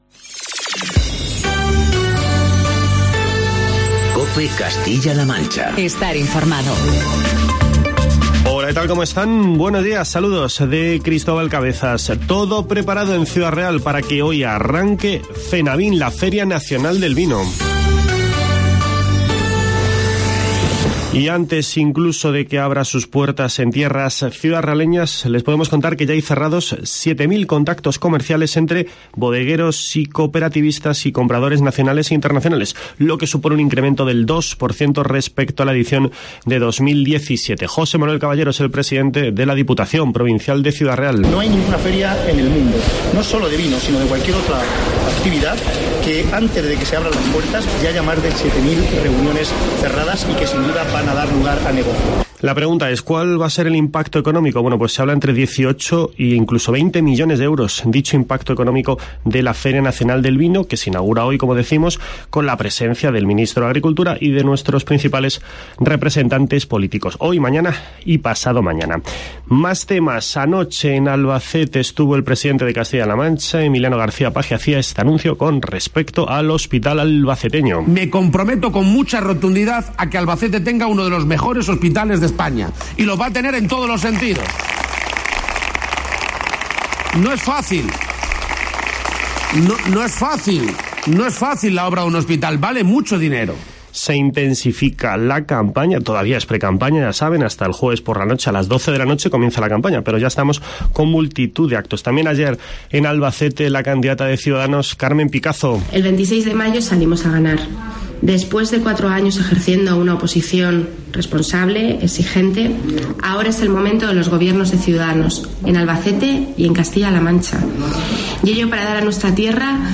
Informativo matinal de COPE Castilla-La Mancha.